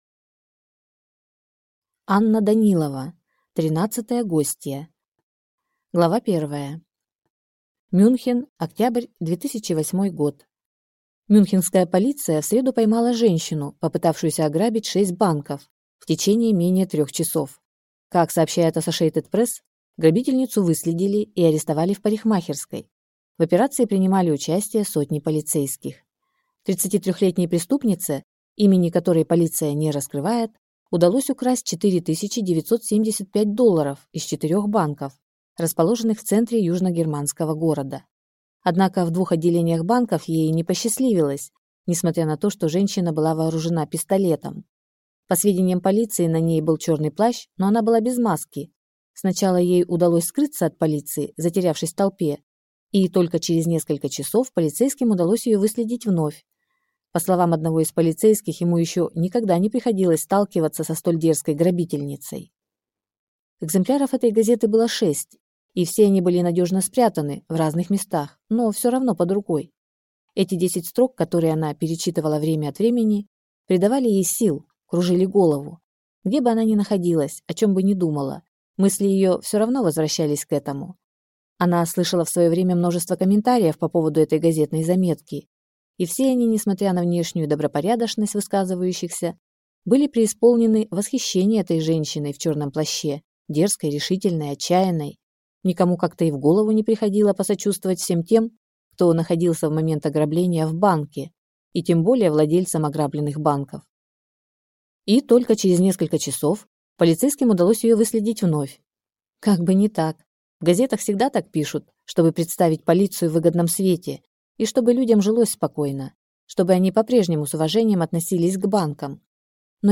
Аудиокнига Тринадцатая гостья | Библиотека аудиокниг